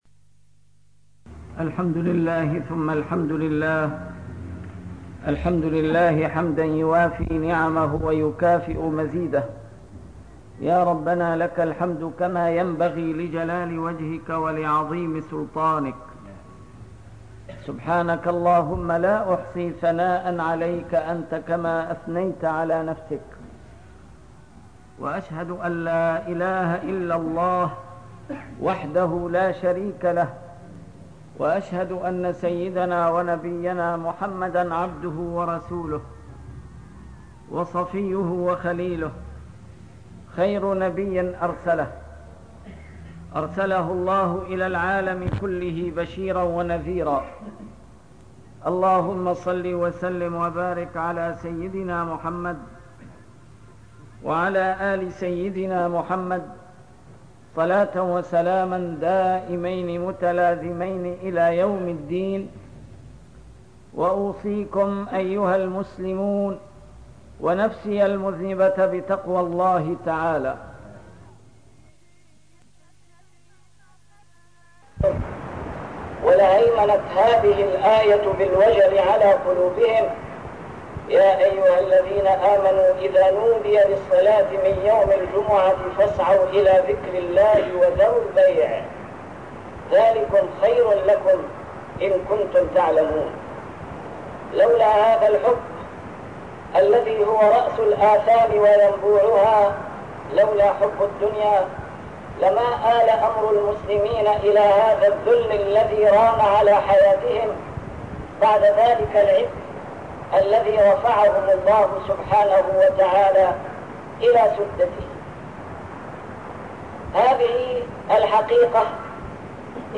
A MARTYR SCHOLAR: IMAM MUHAMMAD SAEED RAMADAN AL-BOUTI - الخطب - حب الدنيا رأس كل خطيئة